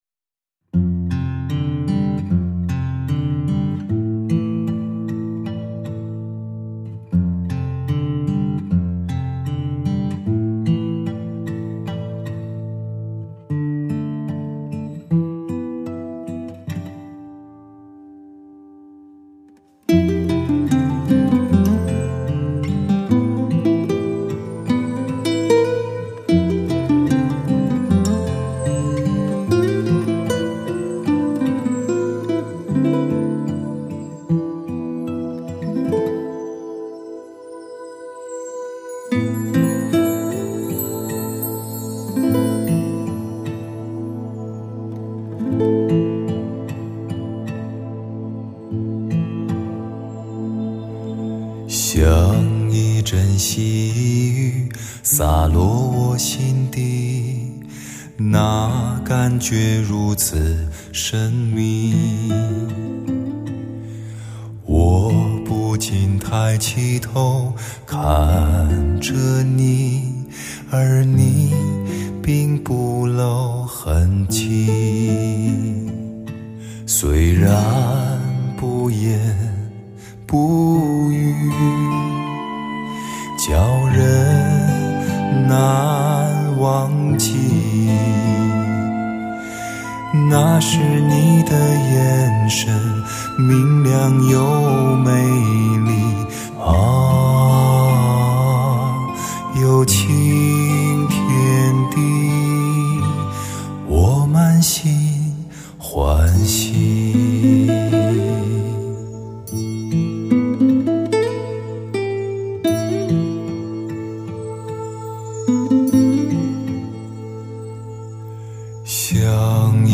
男声发烧碟中少有的佳作之一！